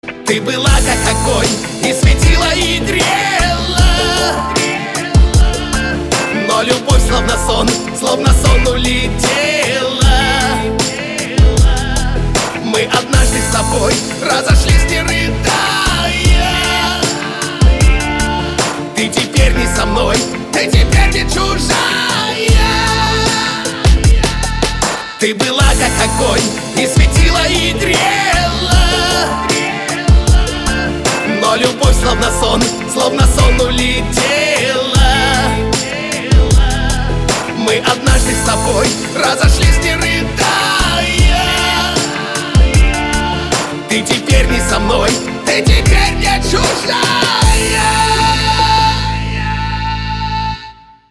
шансон